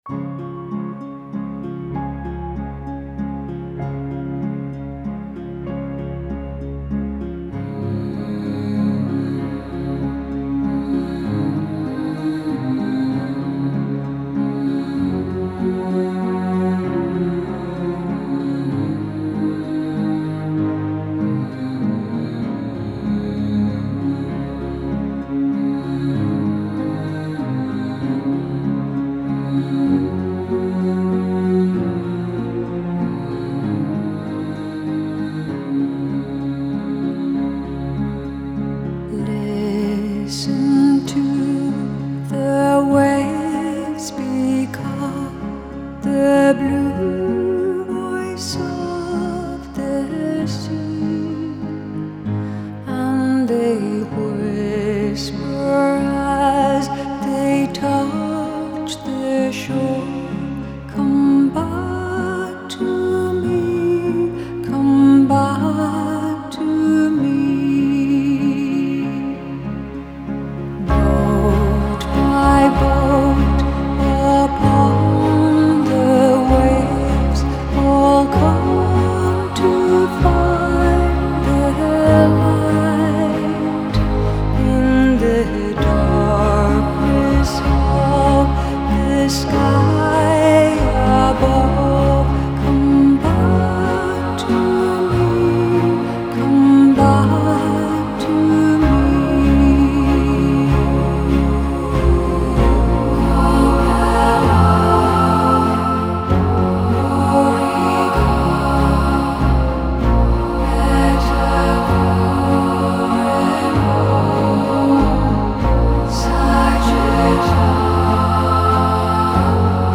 UK • Genre: New Age • Style: Celtic